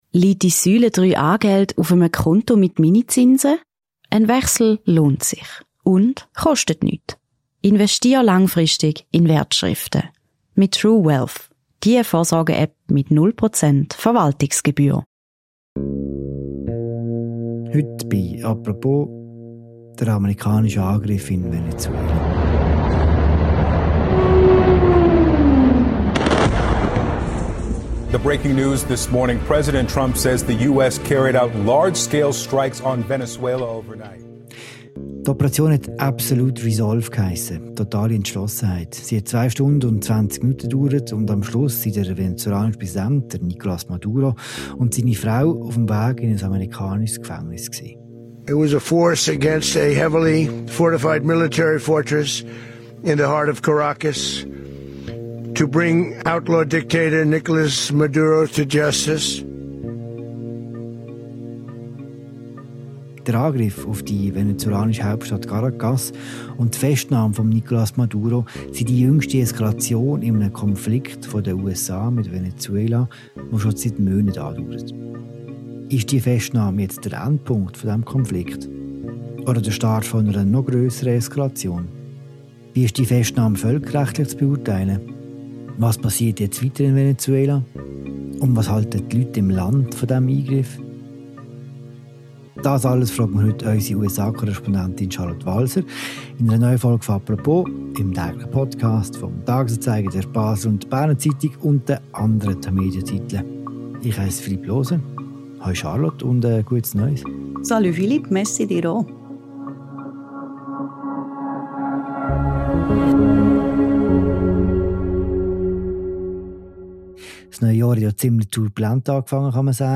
Sie ist zu Gast in einer neuen Folge des täglichen Podcasts «Apropos».